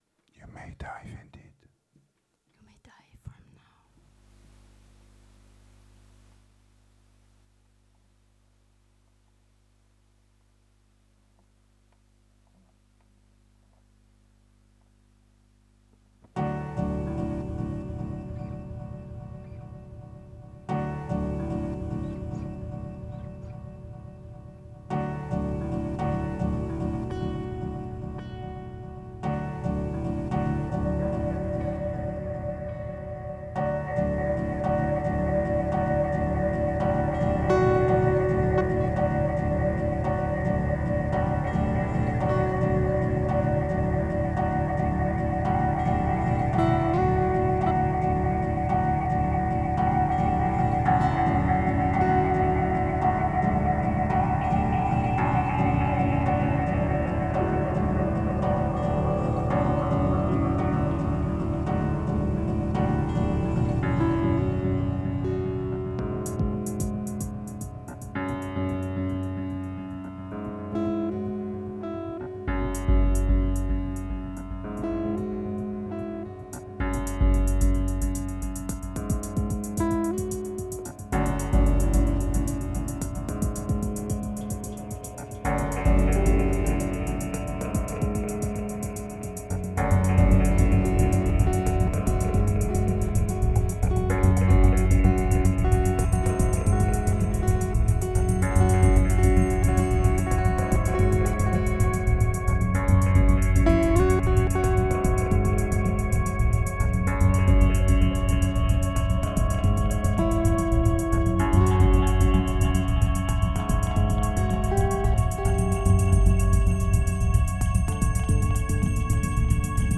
2049📈 - 38%🤔 - 111BPM🔊 - 2012-09-30📅 - -9🌟